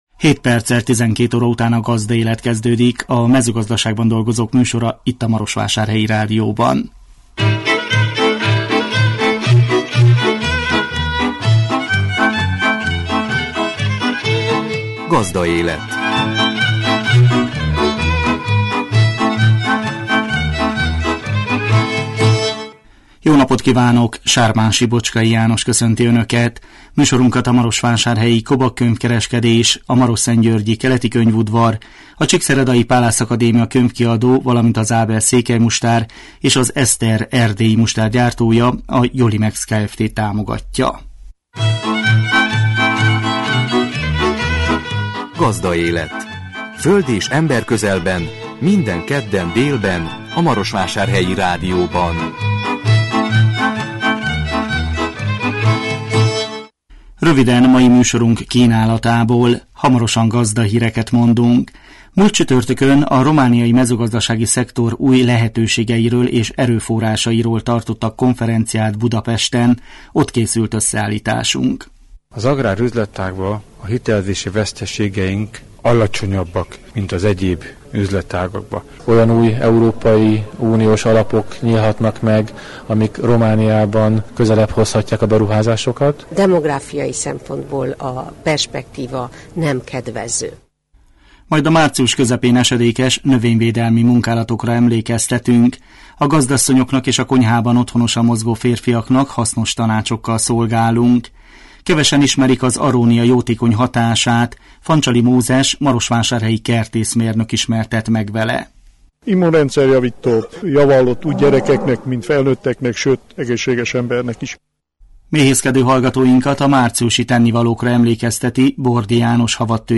A 2017 március 14-én jelentkező műsor tartalma: Gazdahírek, Múlt csütörtökön a romániai mezőgazdasági szektor új lehetőségeiről és erőforrásairól tartottak konferenciát Budapesten. Ott készült összeállításunk. Majd a március közepén esedékes növényvédelmi munkálatokra emlékeztetünk.